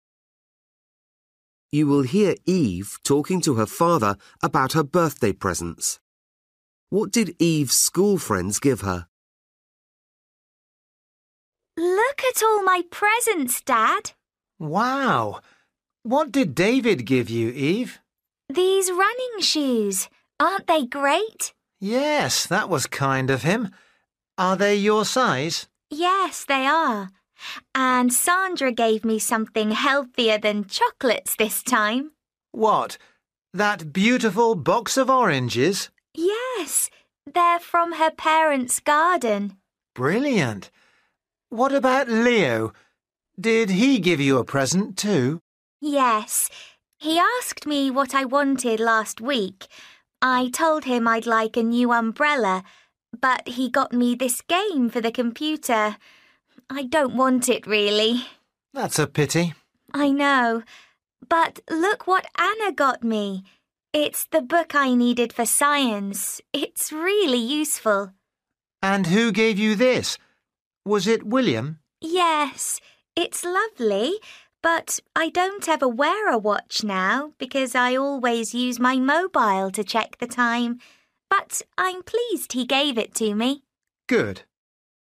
You will hear Eva talking to her father about her birthday presents.